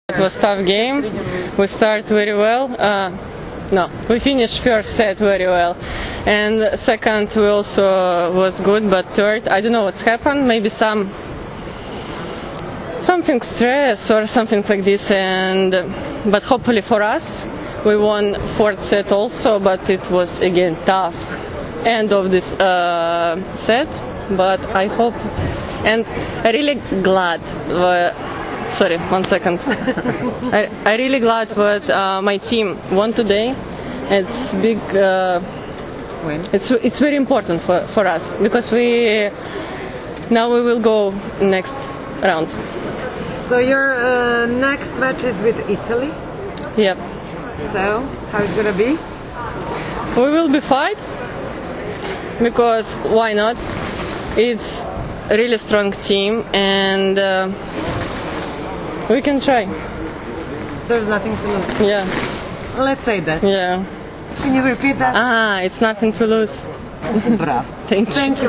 Izjava